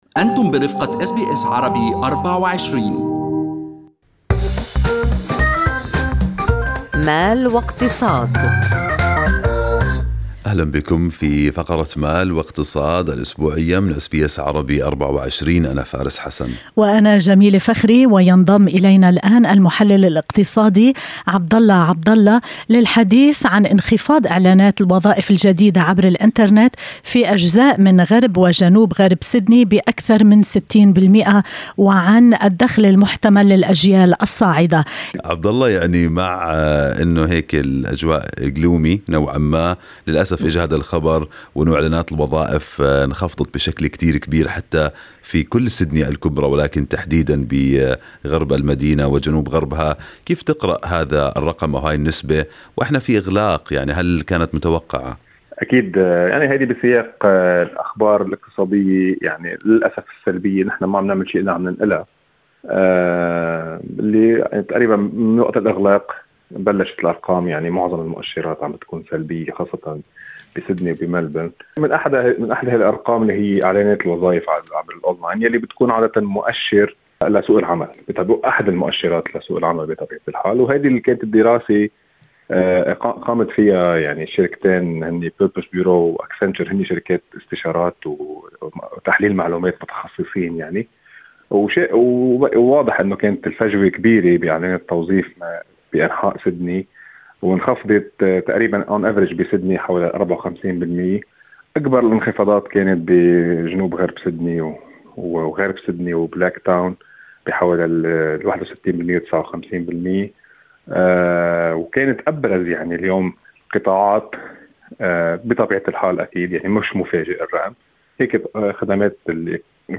وفنّد المحلل الاقتصادي